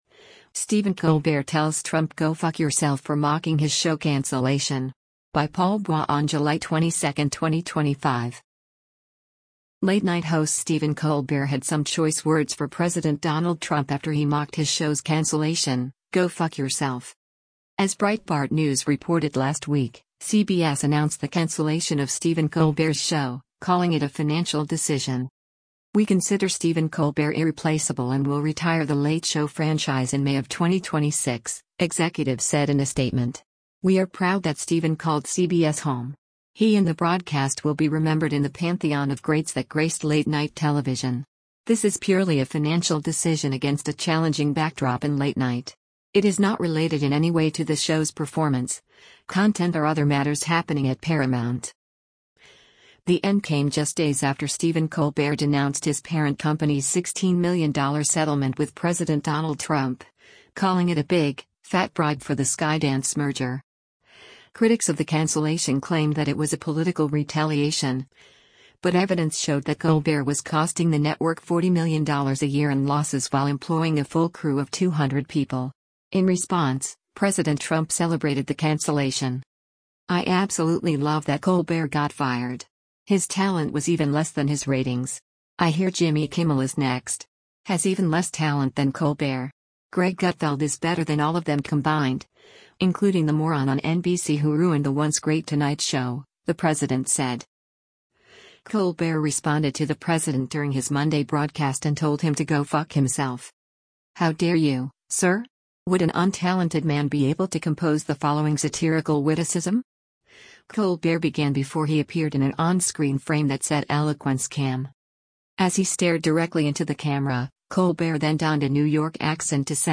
Colbert responded to the president during his Monday broadcast and told him to “go fuck” himself.
As he stared directly into the camera, Colbert then donned a New York accent to say: “Go fuck yourself.” The audience applauded.